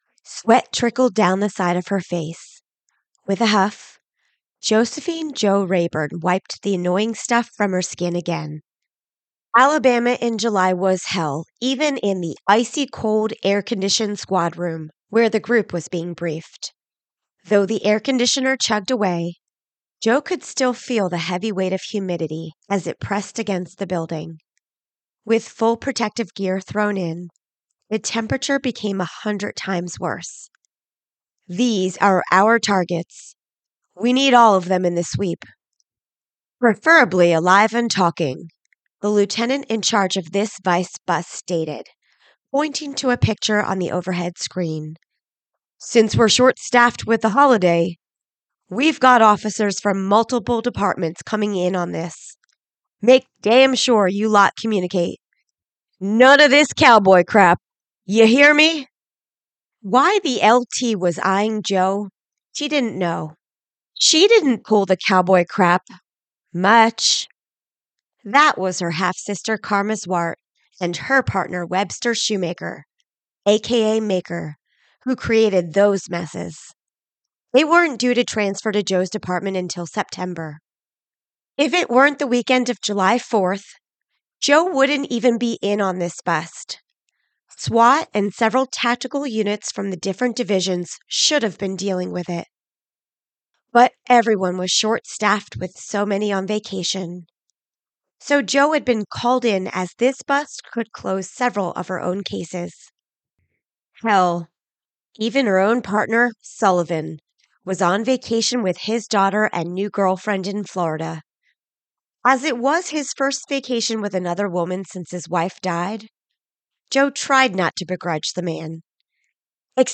Skinned Audiobook – CeeRee Fields